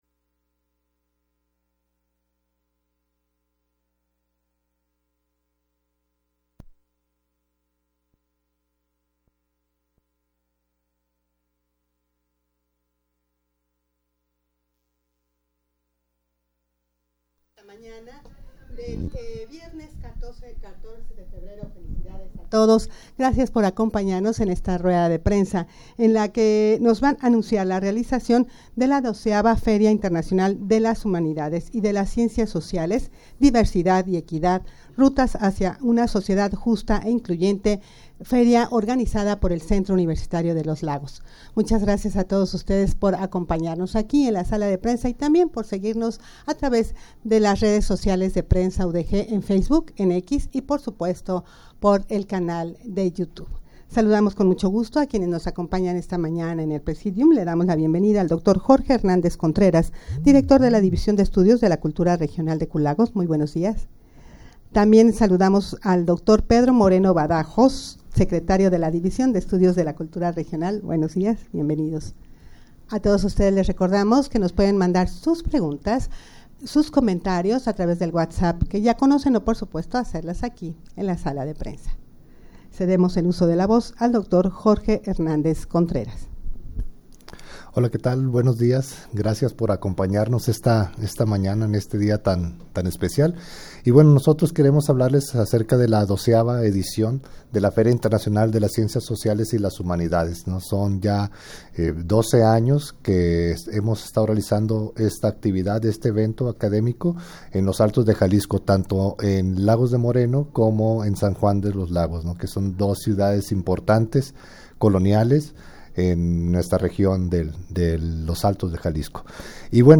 Audio de la Rueda de Prensa